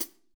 Index of /90_sSampleCDs/Best Service - Real Mega Drums VOL-1/Partition H/DRY KIT 2 GM